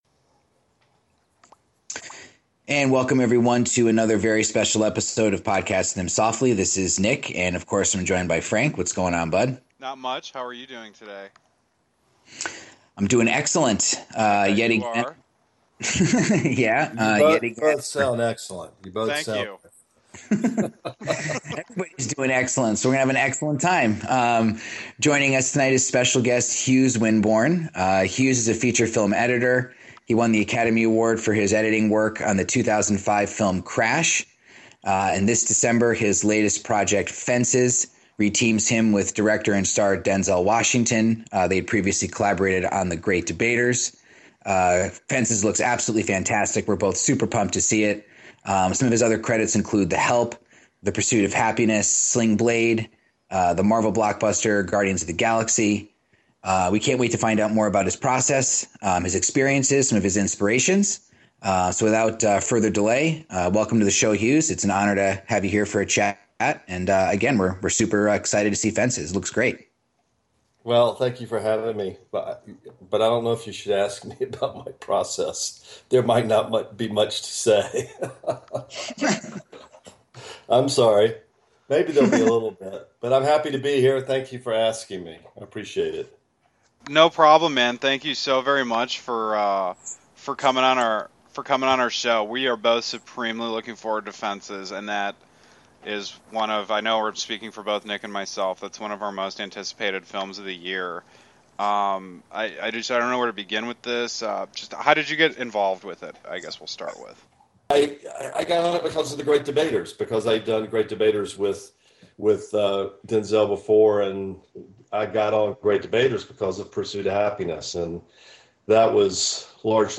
Podcasting Them Softly is honored to present a discussion with feature film editor Hughes Winborne!